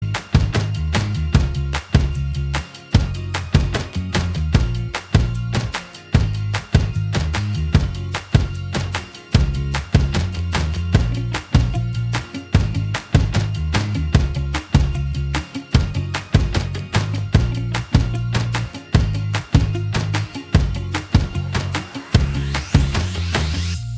Minus Main Guitar Pop (2010s) 3:27 Buy £1.50